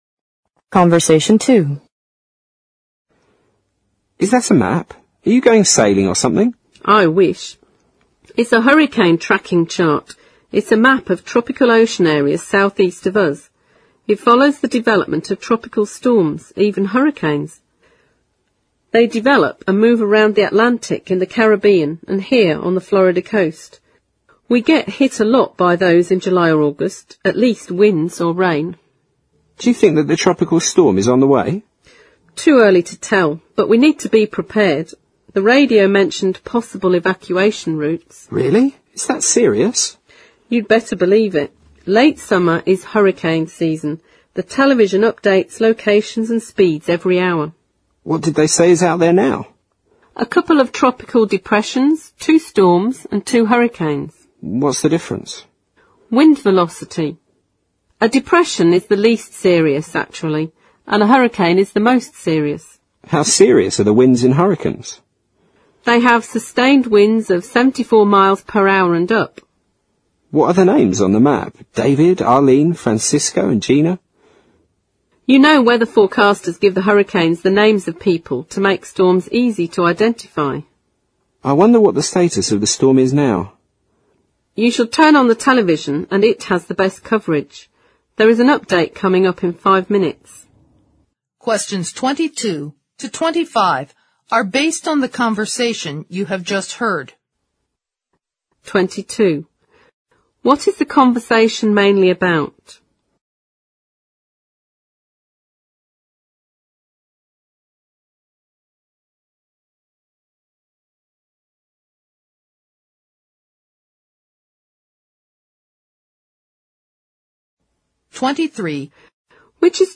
Conversation Two